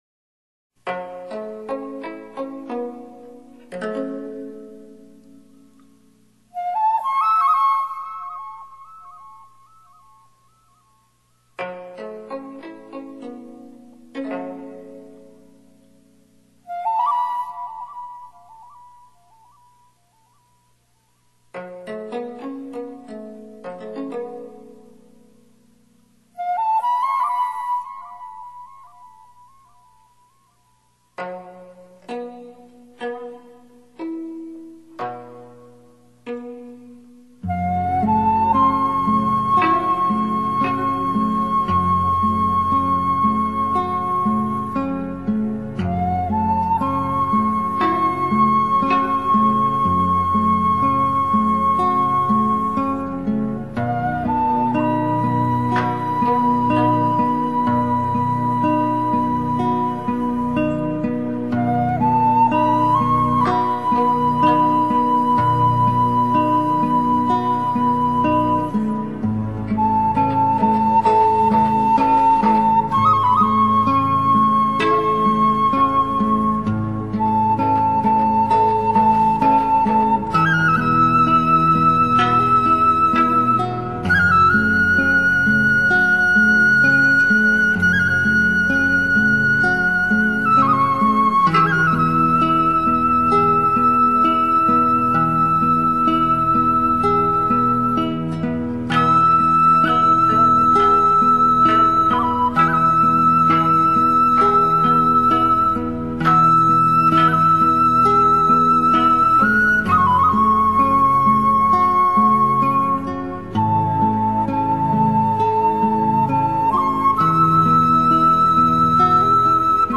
산사음악